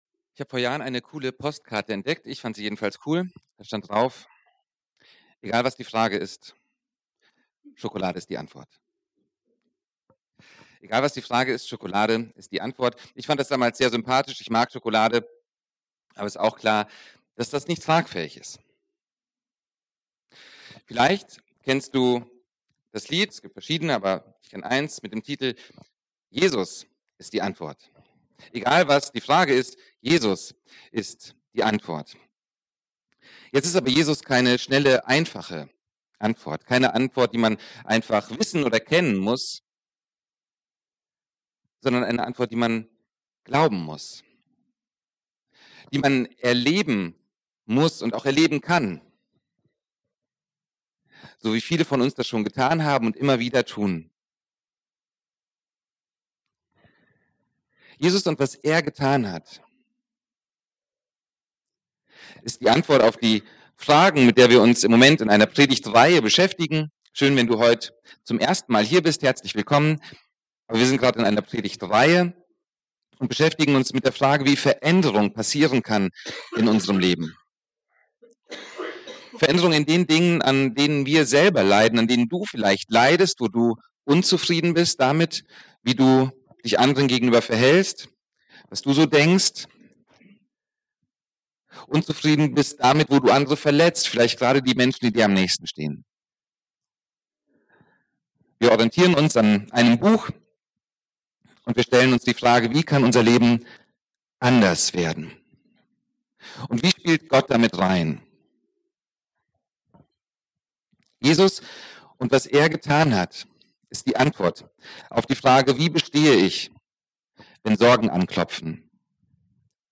In dieser Predigtserie wollen wir entdecken, wie echte Veränderung tatsächlich möglich werden kann.